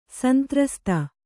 ♪ santrasta